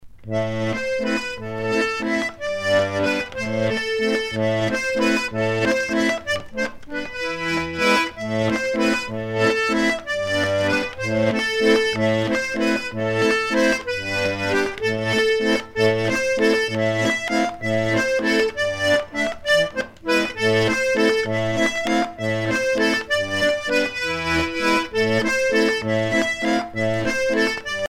danse : valse